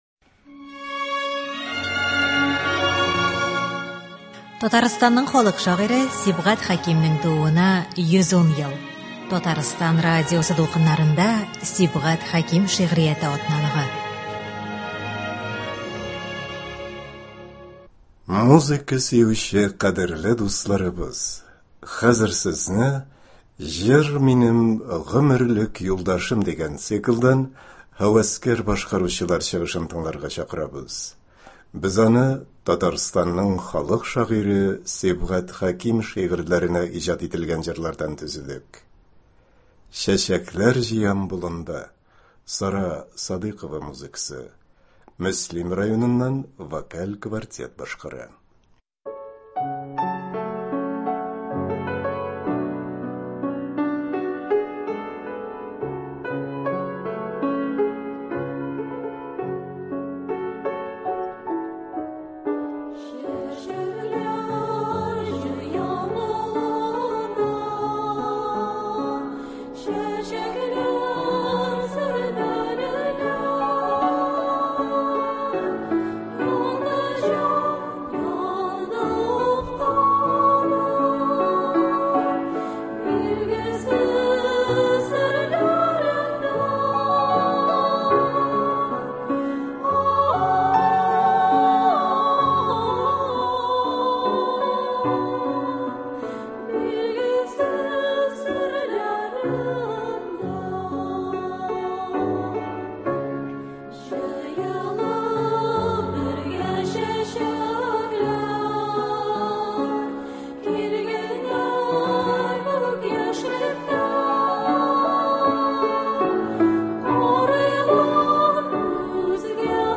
Һәвәскәр җырчылар башкаруында Сибгат Хәким җырлары.
Концерт (29.11.21)